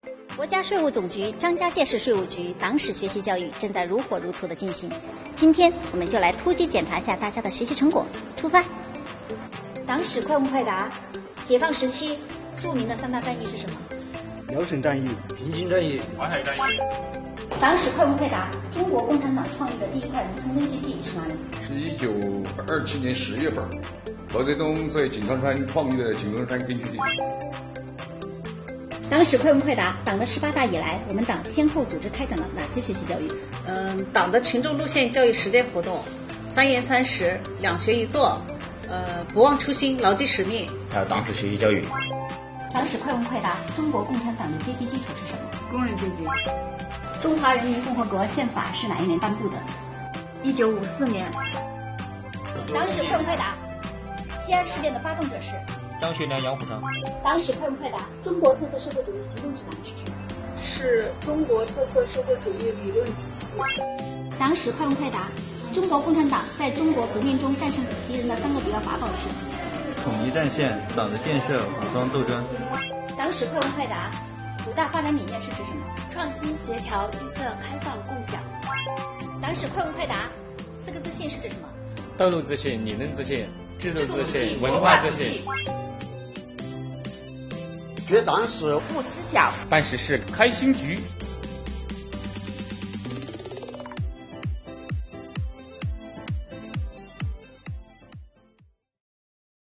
下面跟随税务小姐姐去看看